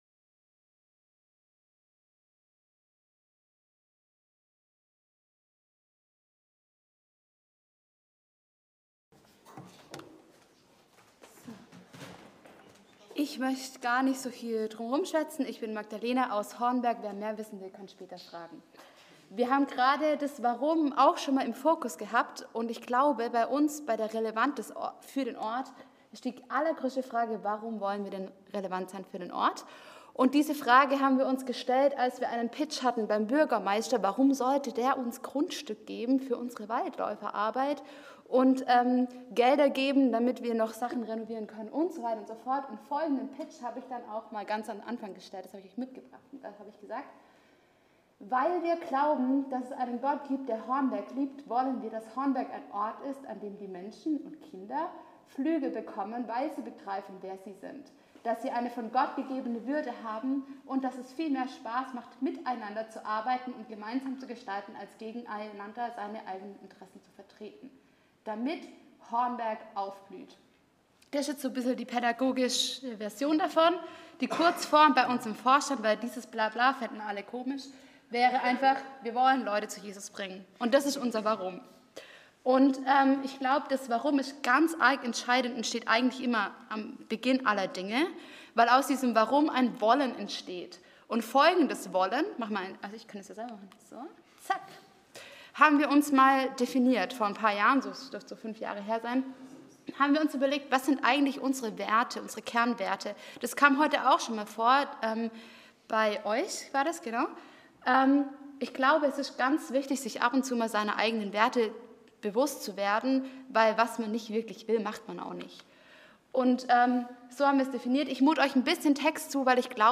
Themenbereich: Vortrag